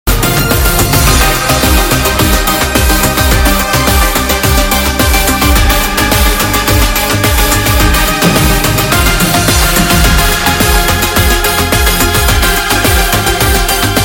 instrumental music